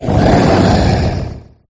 barraskewda_ambient.ogg